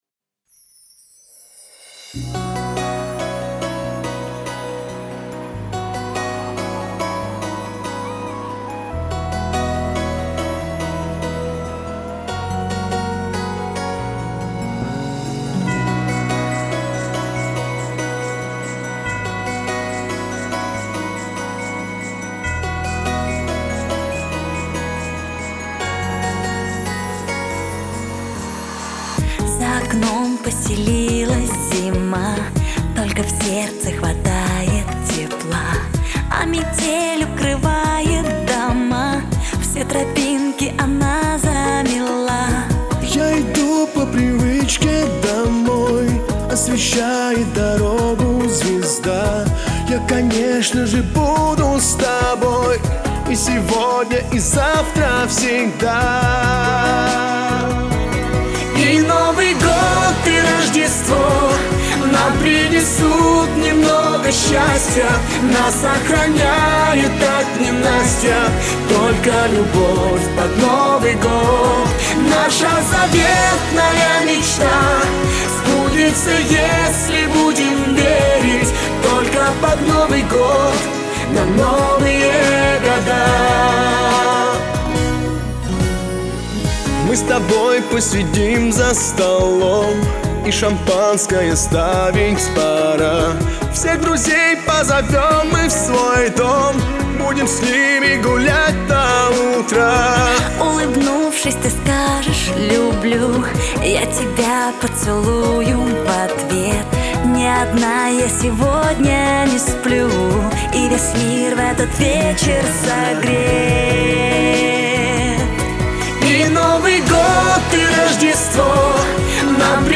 это яркий и радостный трек в жанре поп